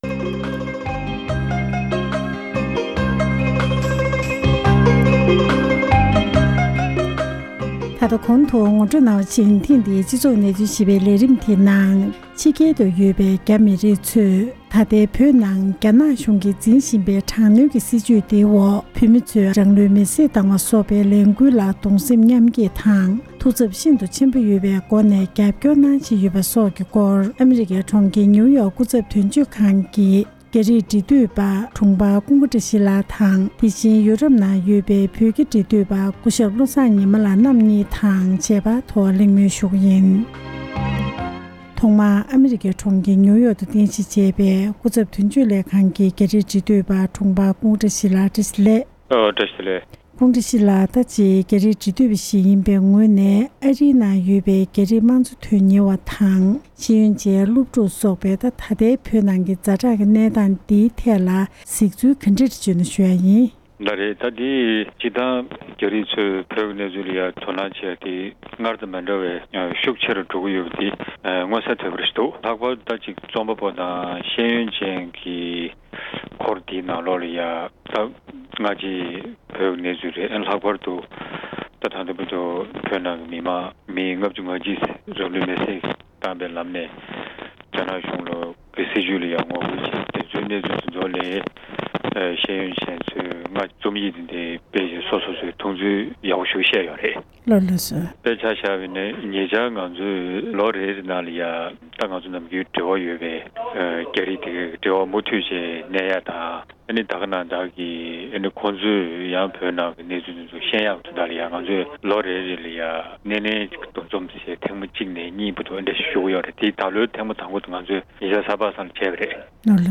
ཨ་རིའི་དང་ཡོ་རོབ་ནང་གི་རྒྱ་རིགས་རིགས་མཐུད་པར་གནས་འདྲི་ཞུས་པ་ཞིག་ལ་གསན་རོགས་ཞུ༎